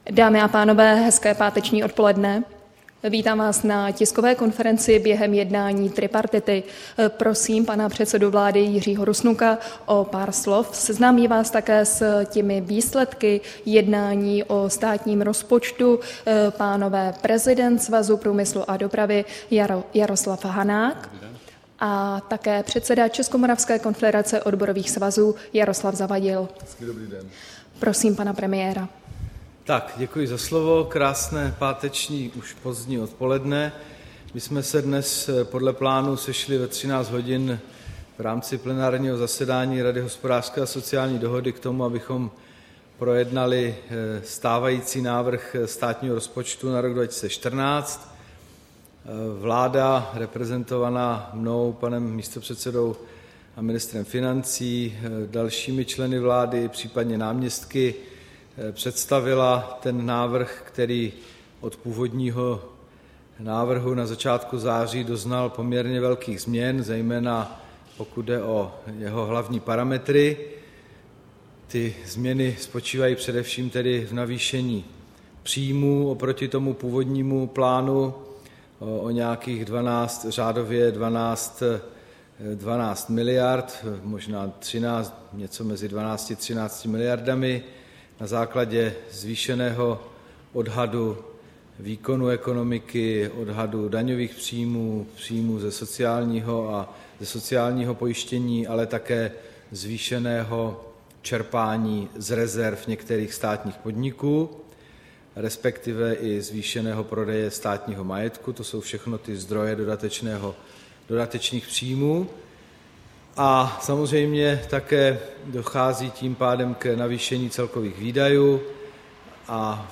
Tisková konference po jednání tripartity, 20. září 2013